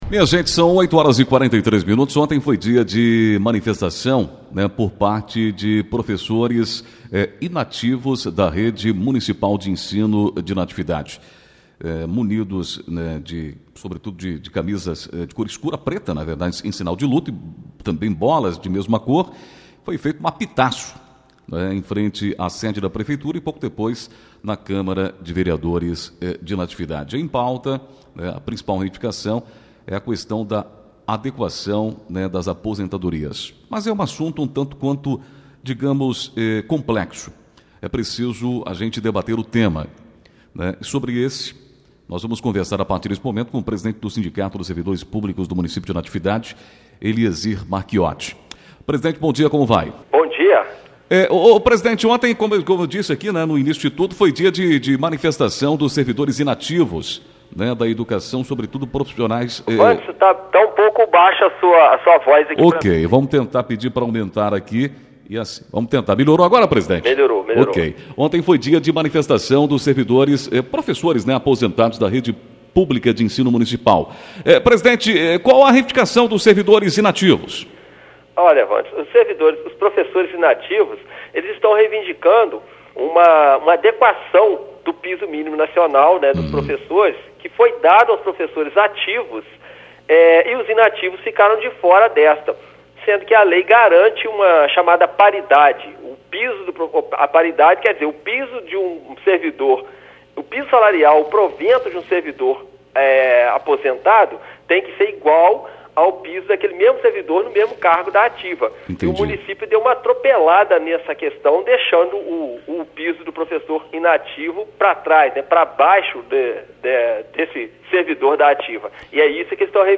ENTREVISTA-SINDICATO.mp3